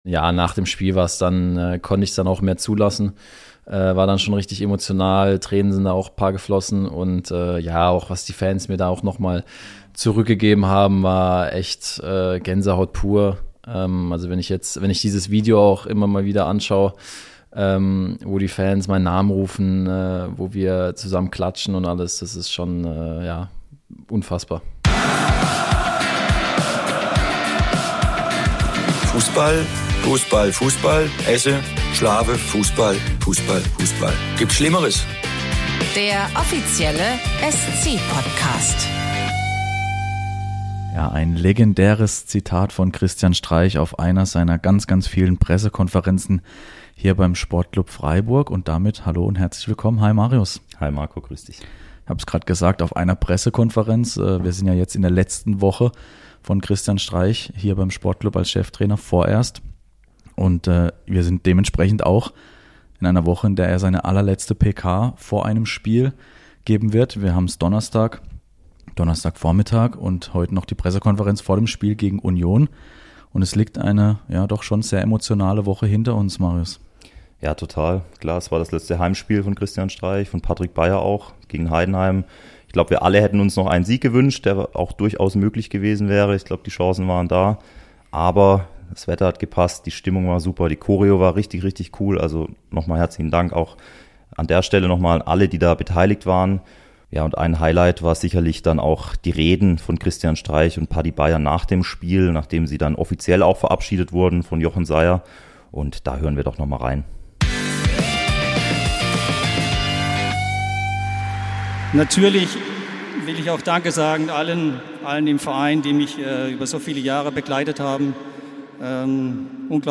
Im Interview beschreibt er, wie wohl er sich in der neuen Rolle fühlt, wie sein Verhältnis zu Christian Streich ist und warum die U19-Spieler vor dem anstehenden Pokalfinale die Finger vom Schokobrunnen lassen sollten.